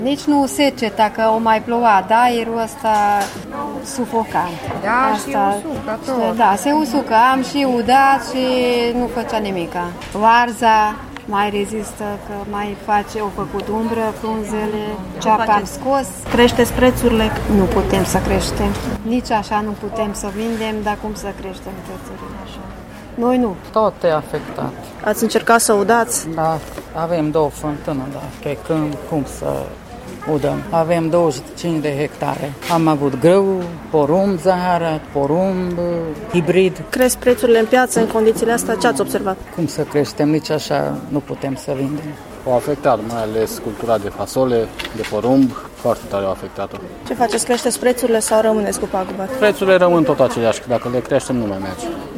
Cu toate acestea, spun ei, prețurile nu au crescut pentru că și așa vânzările sunt slabe: